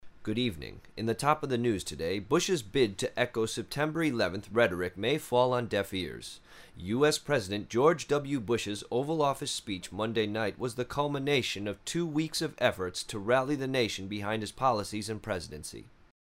高くはっきりとした声。